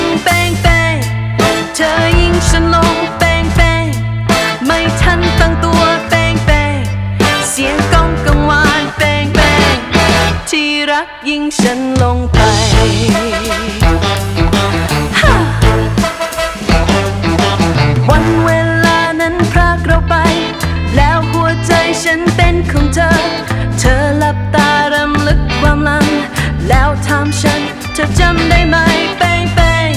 Жанр: Соундтрэки / Классика